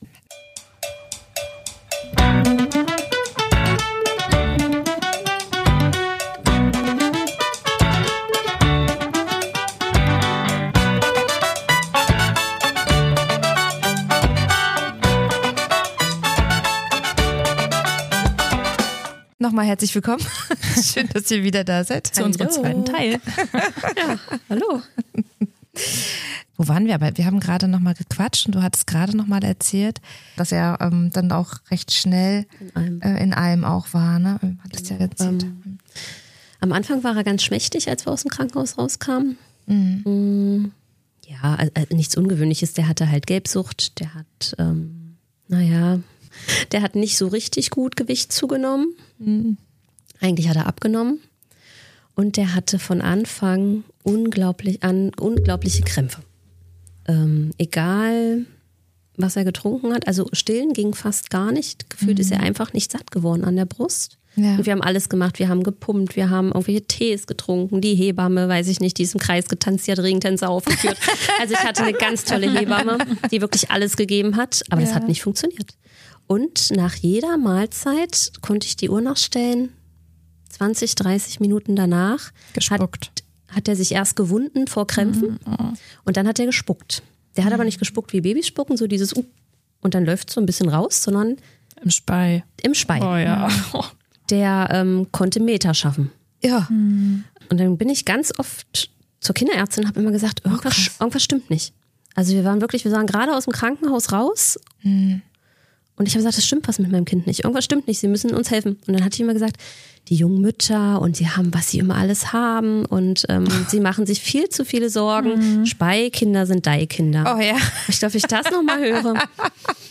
Beschreibung vor 8 Monaten Zwei Freundinnen, zwei Sichtweisen – eine ehrliche Folge über Erziehung, Intuition und kindliche Entwicklung.